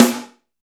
34 SNARE 3-L.wav